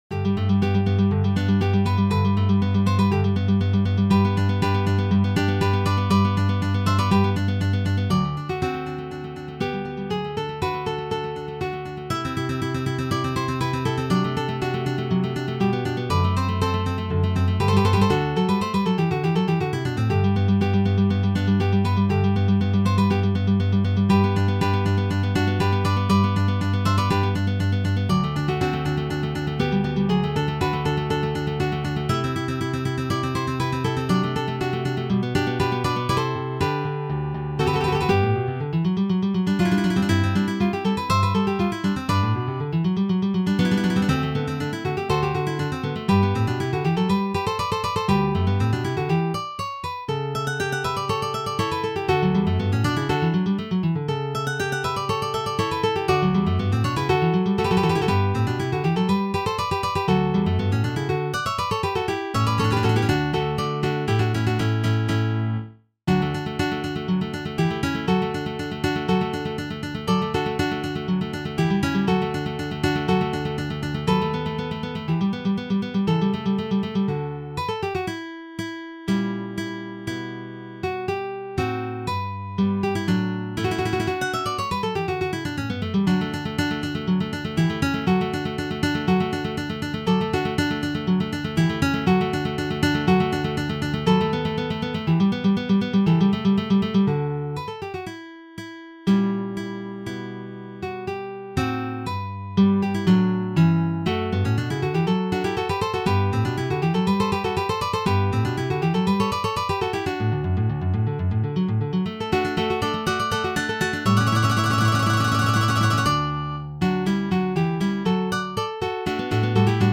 I  Allegro